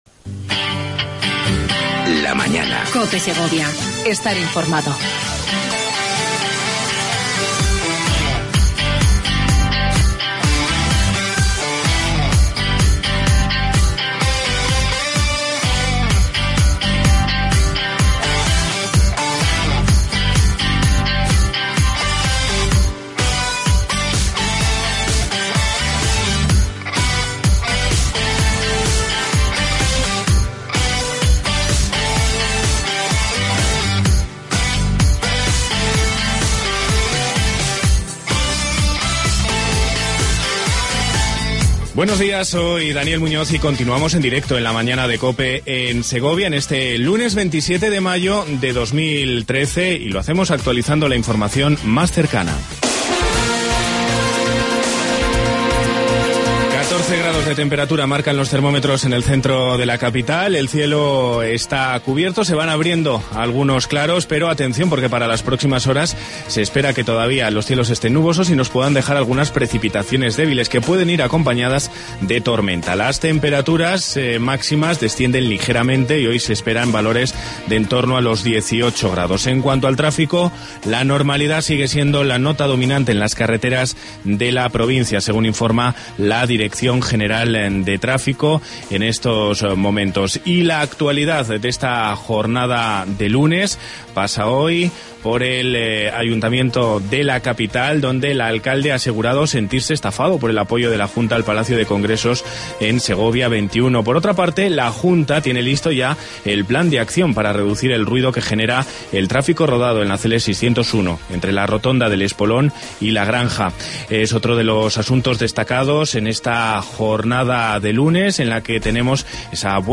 AUDIO: Entrevista con Pilar Sanz, Subdelegada de gobierno en Segovia.Quiniela Camfe Castellana